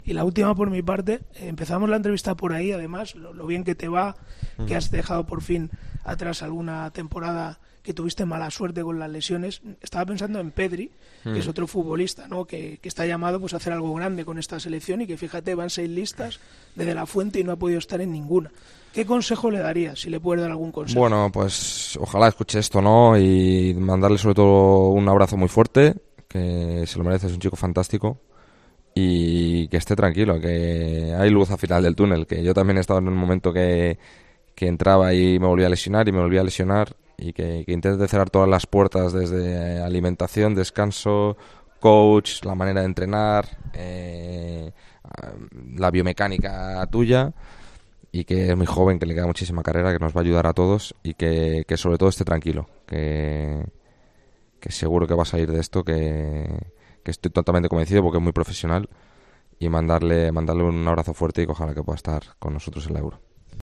Dani Carvajal no quiso olvidarse de Pedri durante la entrevista que concedió este martes a El Partidazo de COPE, desde el cuartel general de la Selección Española.
ESCUCHA EL MENSAJE QUE DANI CARVAJAL MANDÓ A PEDRI EN EL PARTIDAZO DE COPE